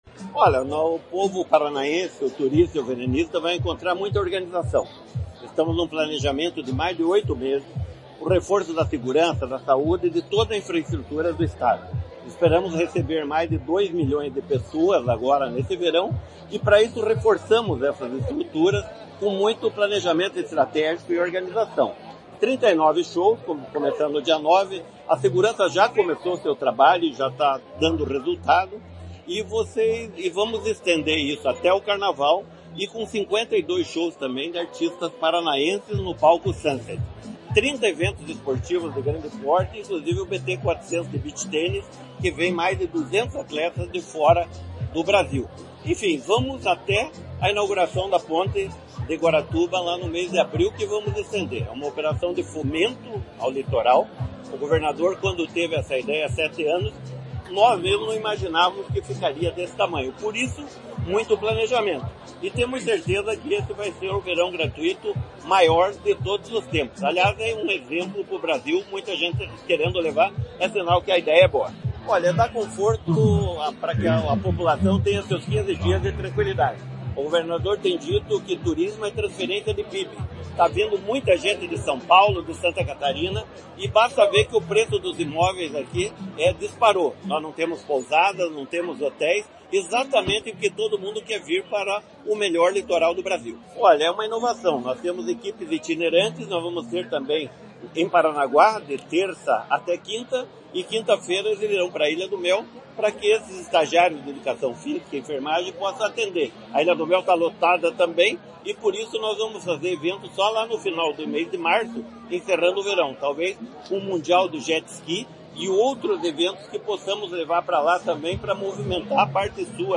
Sonora do secretário do Esporte, Hélio Wirbiski, sobre o lançamento do Verão Maior Paraná